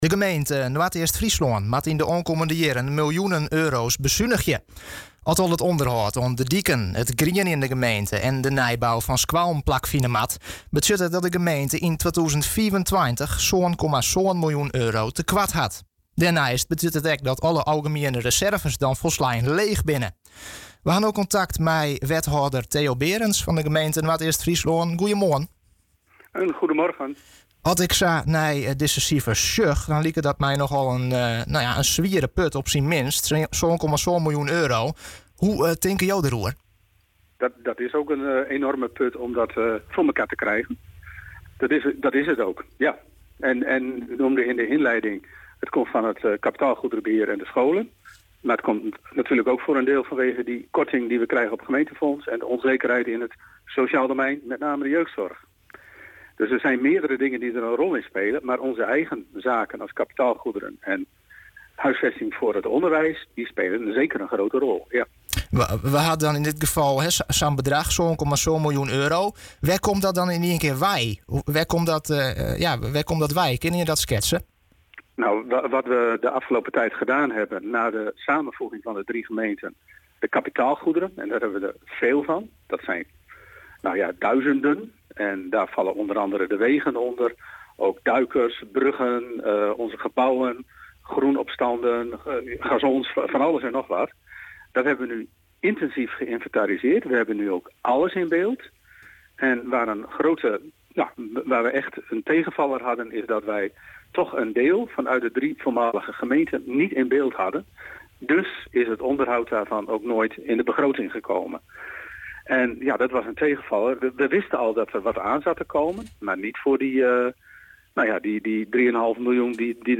KOLLUM/DE WESTEREEN – De gemeente Noardeast-Fryslân moet in de aankomende jaren fors bezuinigen, willen zij niet tegen een miljoenentekort aan kijken. “Het is ook een enorme klus om dat voor elkaar te krijgen”, aldus wethouder Theo Berends in de Weekend Nieuwsshow.
Wethouder Theo Berends over de financiële positie